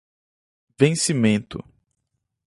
Uitgesproken als (IPA)
/vẽ.siˈmẽ.tu/